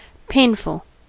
Ääntäminen
France: IPA: [pe.nibl]